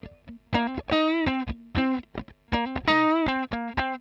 120_Guitar_funky_riff_C_1.wav